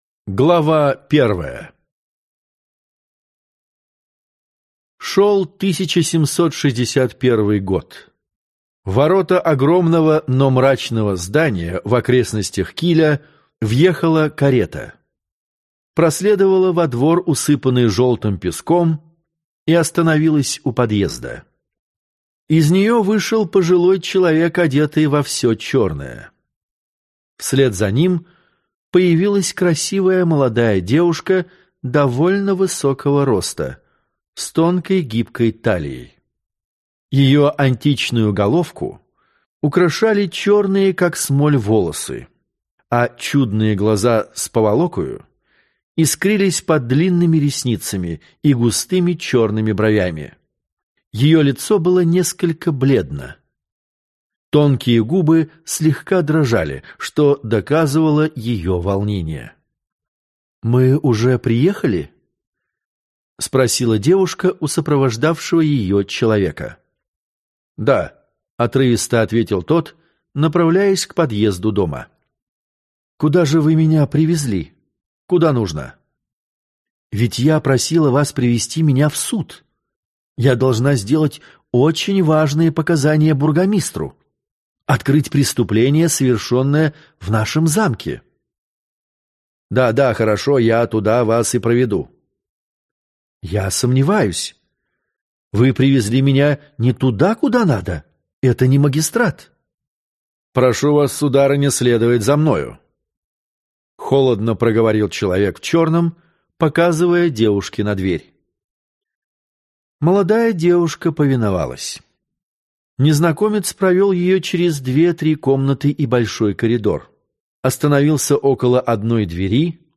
Аудиокнига Авантюристка | Библиотека аудиокниг